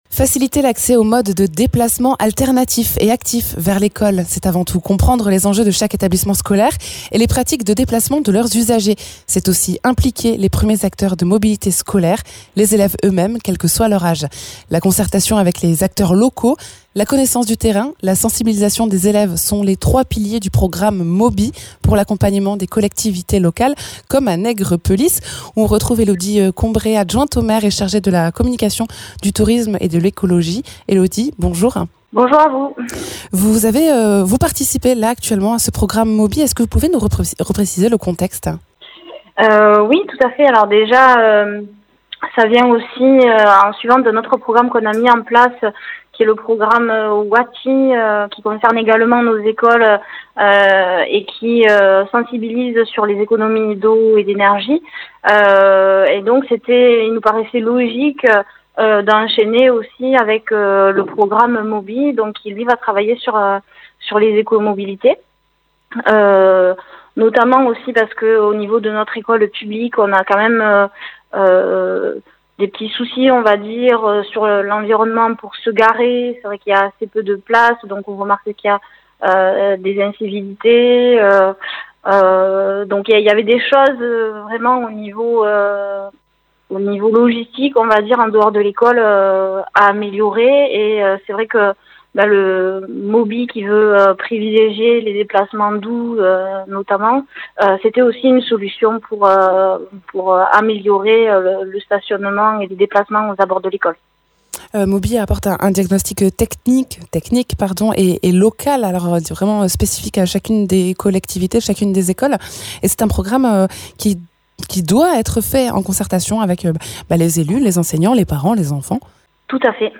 Elodie Combret, adjointe au maire de Nègrepelisse